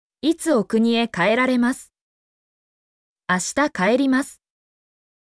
mp3-output-ttsfreedotcom-15_lphTWGe7.mp3